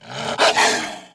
Sound / sound / monster / wolf / attack_1.wav
attack_1.wav